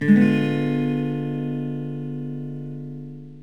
Fdim7.mp3